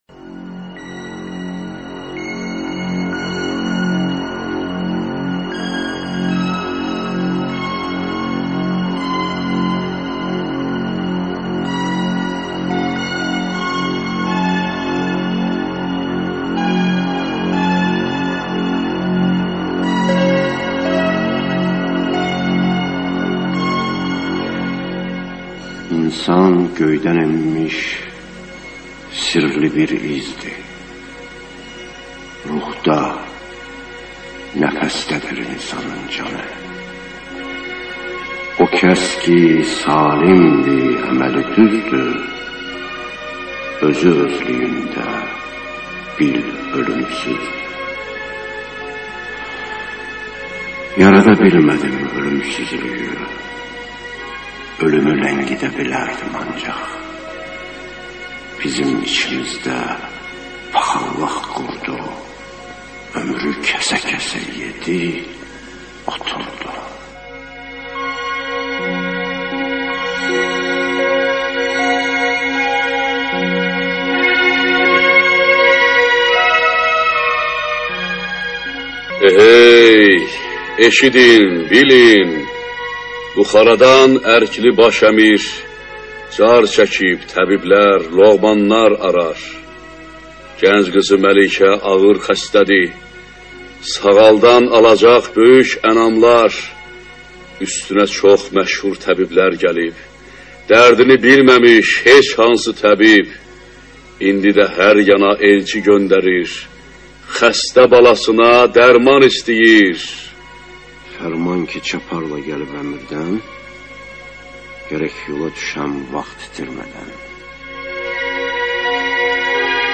ЖанрРадиоспектакли на азербайджанском языке